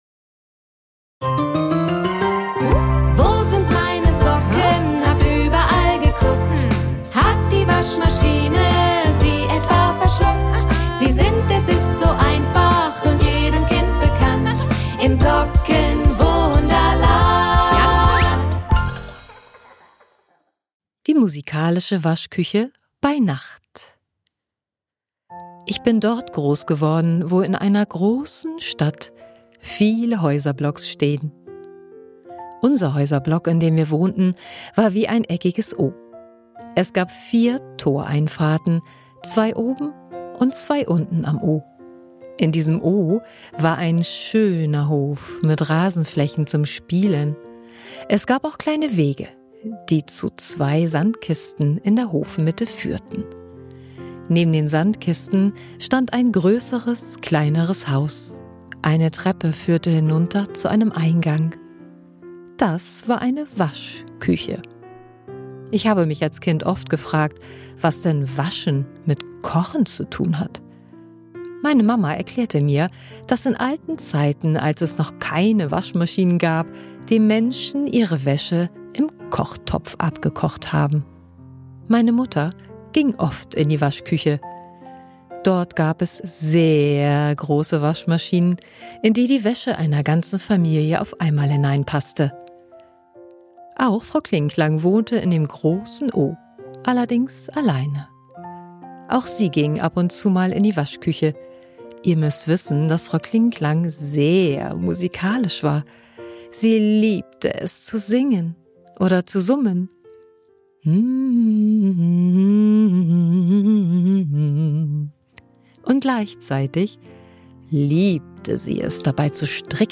Im Sockenwunderland - Ein Hörspiel für Kinderherzen